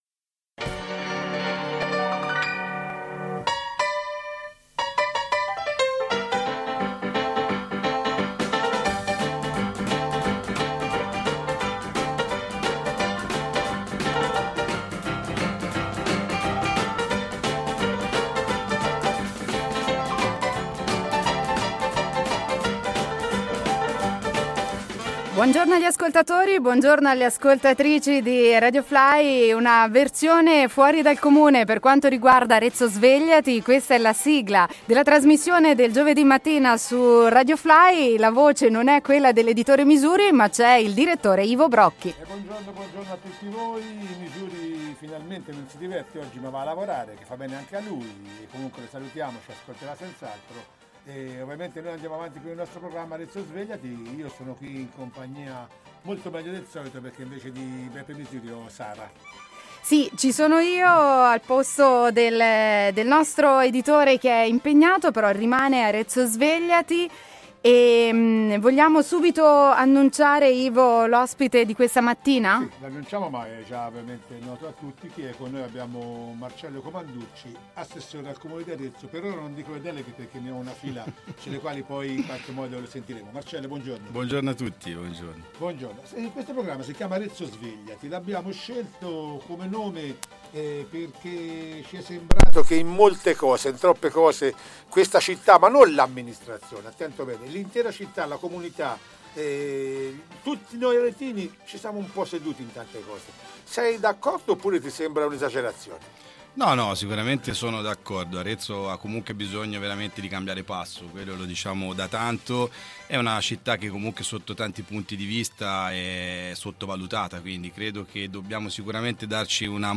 Un interessante dibattito su temi molto importanti per la nostra città.